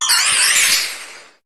Cri de Météno dans sa forme Météore dans Pokémon HOME.